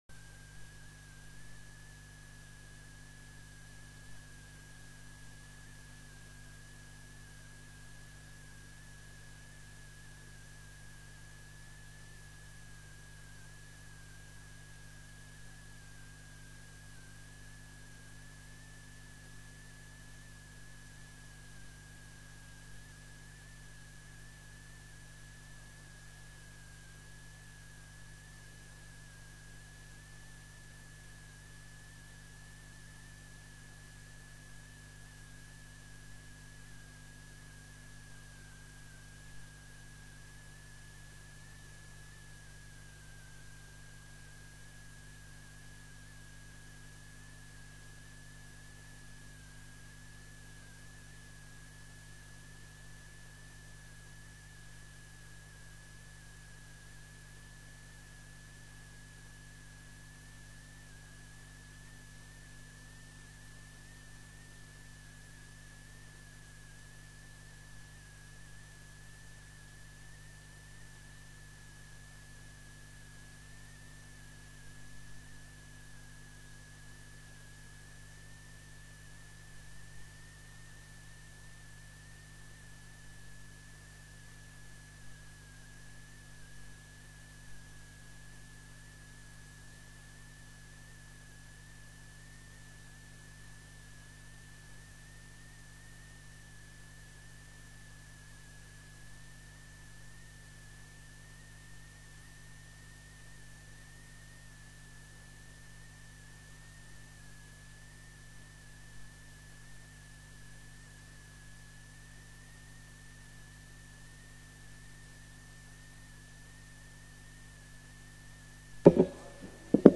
Consiglio Comunale 31 luglio 2019 - Comune di Monteriggioni